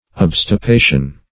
Obstipation \Ob`sti*pa"tion\, n. [L. obstipatio a close